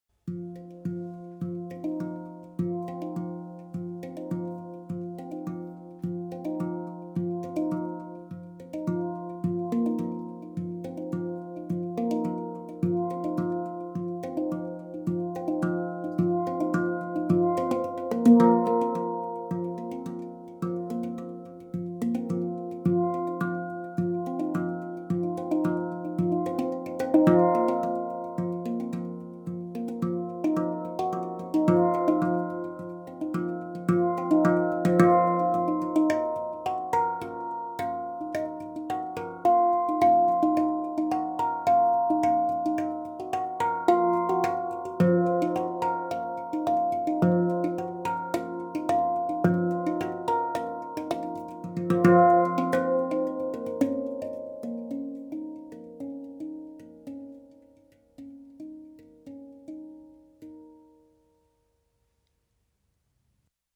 MEINL Sonic Energy Harmonic Art Handpan Akebono - Tuning: F, A#, C, C#, D#, F, F#, A# (HD4)
The Harmonic Art Handpan produces clear and pure notes when struck by the hand. The tone is pleasing, soothing, and relaxing and can be used in a variety of settings both for performance and therapy. Each one is tuned with a center note and seven additionally notes allowing the player to work within a musical scale both as a solo instrumentalist and with a group.